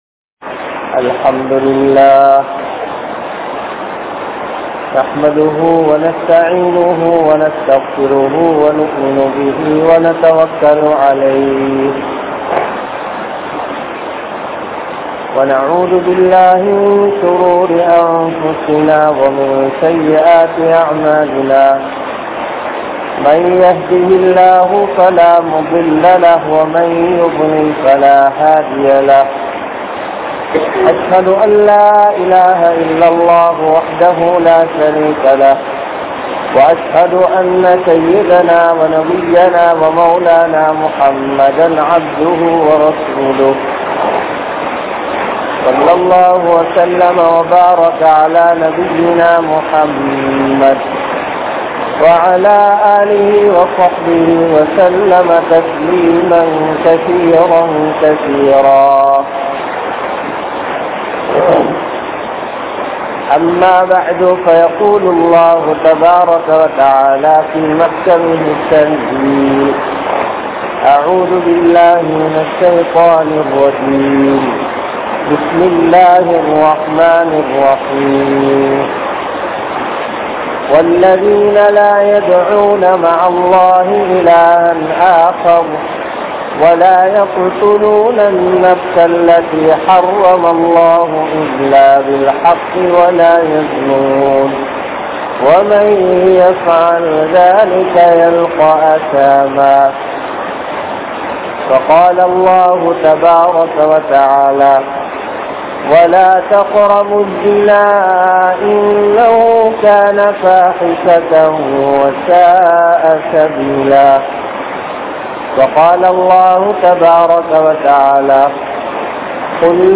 Vifachcharathai Nerungatheerhal (விபச்சாரத்தை நெருங்காதீர்கள்) | Audio Bayans | All Ceylon Muslim Youth Community | Addalaichenai
Muhiyadeen Jumua Masjidh